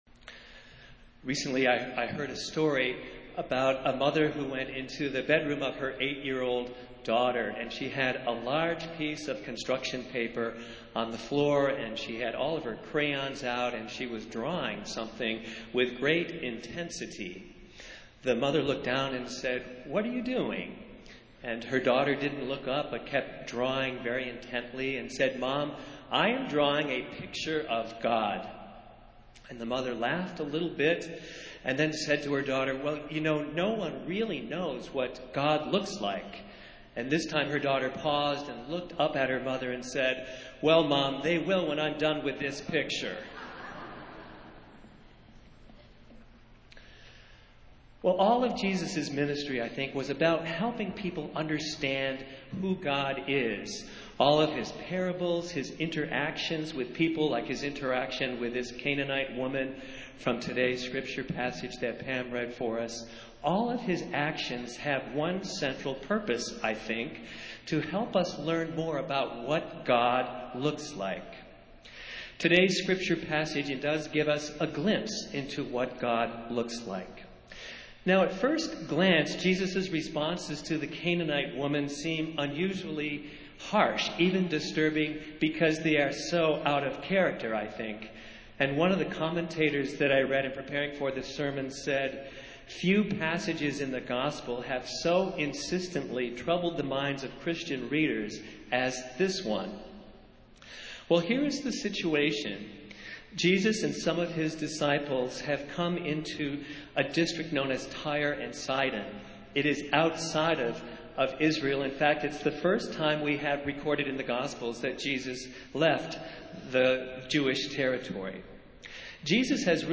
Festival Worship - Tenth Sunday after Pentecost